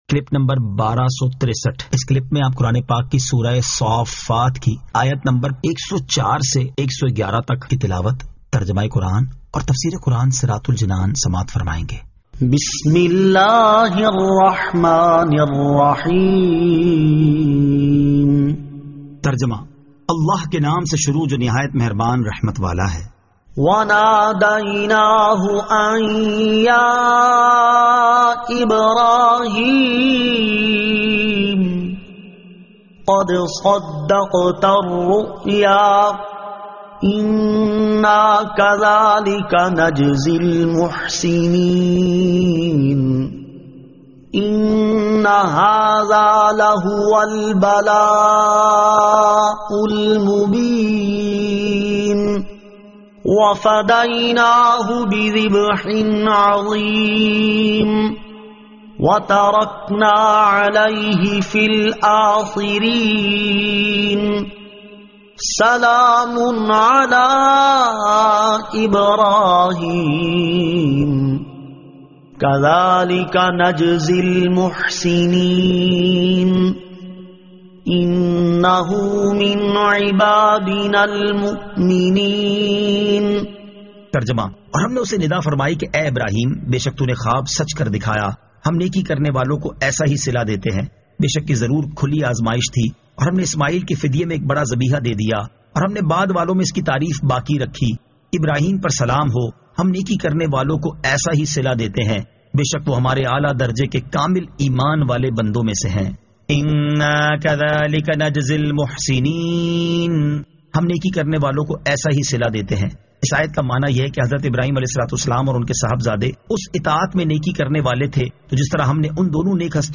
Surah As-Saaffat 104 To 111 Tilawat , Tarjama , Tafseer